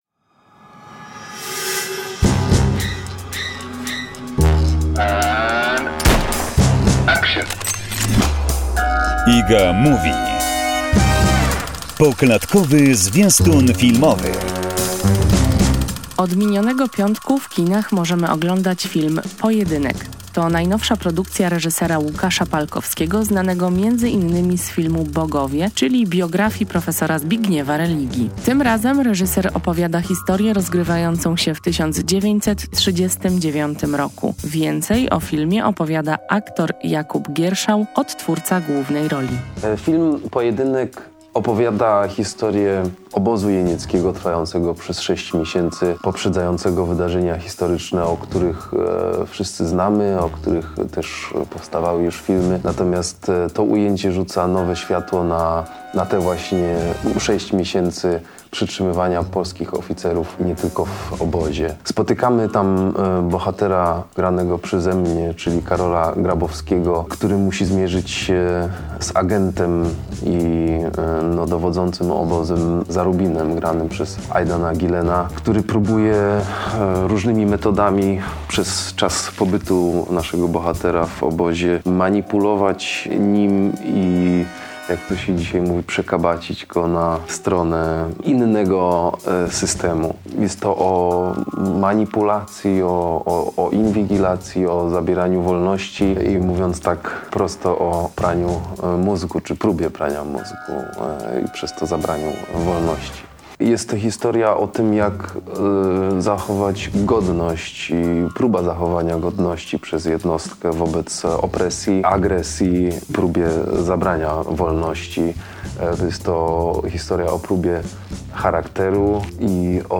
Tym razem reżyser opowiada historię rozgrywającą w 1939 roku. Więcej o filmie opowiada aktor Jakub Gierszał, odtwórca głównej roli.